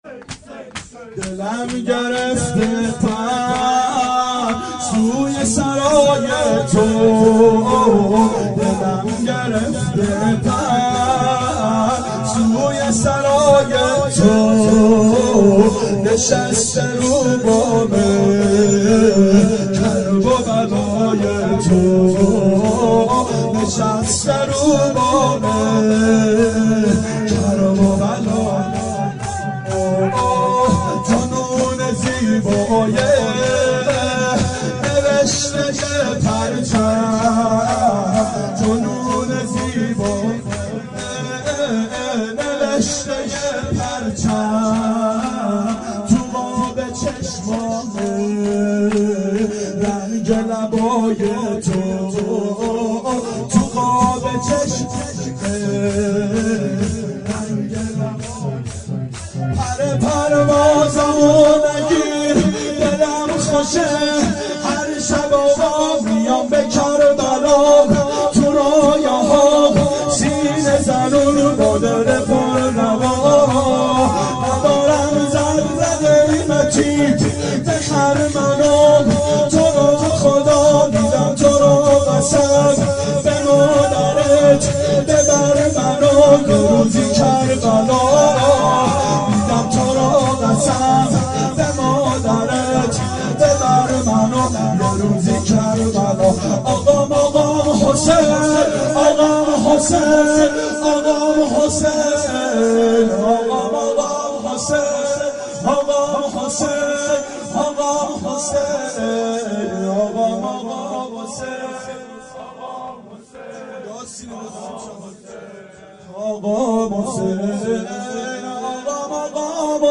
شب شهادت امام هادی علیه السلام 92 محفل شیفتگان حضرت رقیه سلام الله علیها